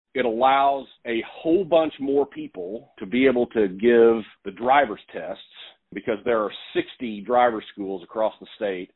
CLICK HERE to listen to details from State Representative Mike Osburn.